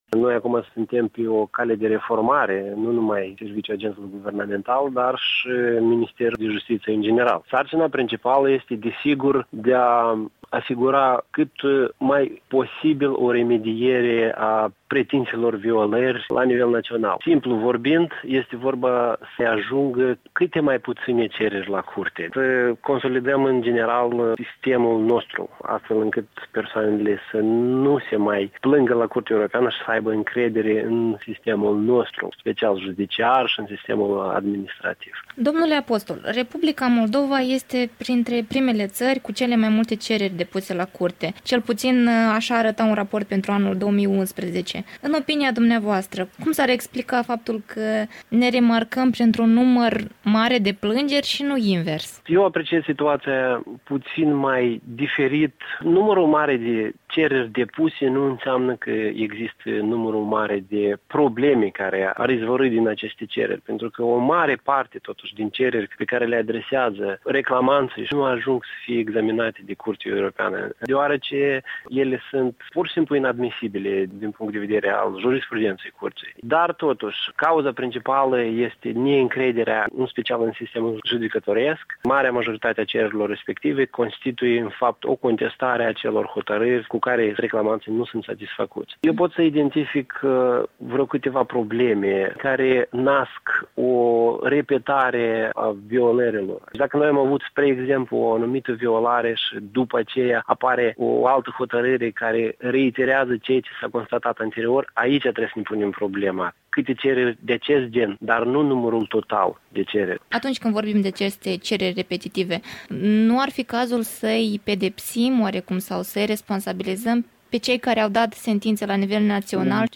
nterviu cu Lilian Apostol, noul reprezenant al Moldovei la Curtea de la Strasbourg.